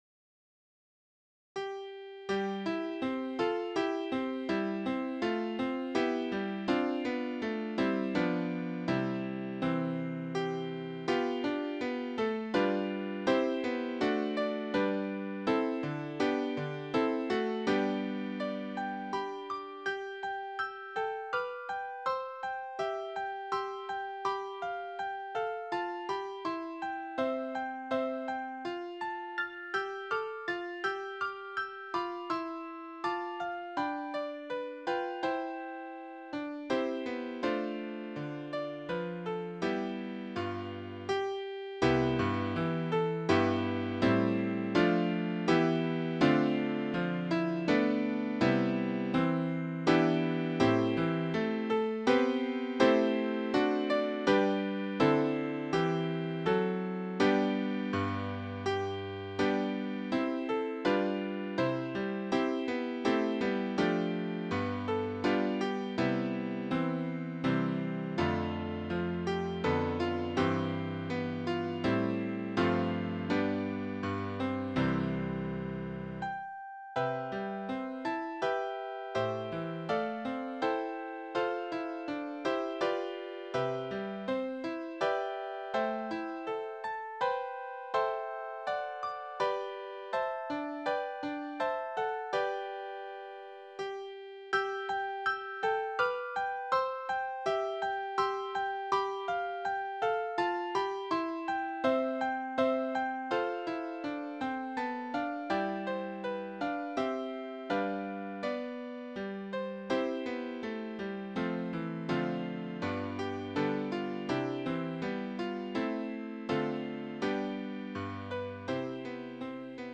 Voicing/Instrumentation: Piano Solo
Instrumental/Instrumented